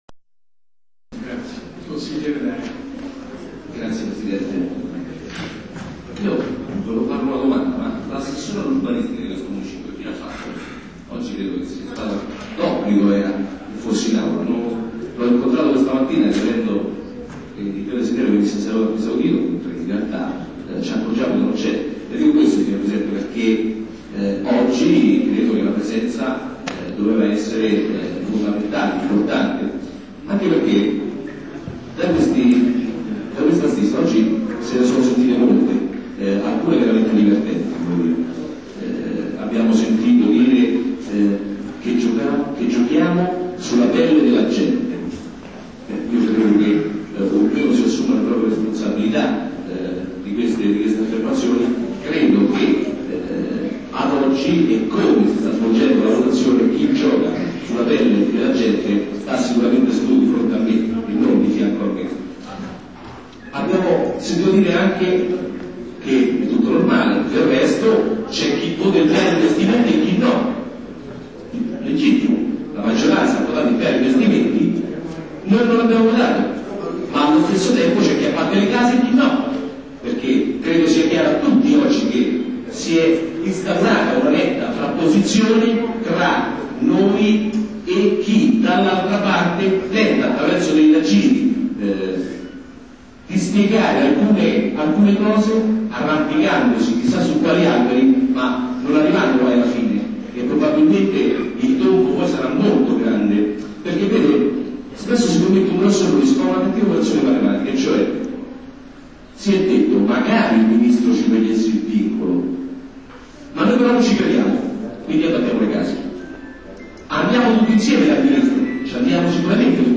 Consiglio Municipale del 31 ottobre 2003 per la proposta di osservazioni al Piano.
Archivio sonoro degli interventi.
Consigliere del Municipio 8°